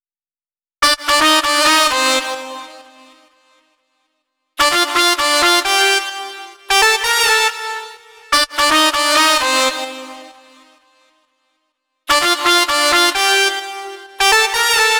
VTDS2 Song Kit 11 Male Going Crazy Brass Lead.wav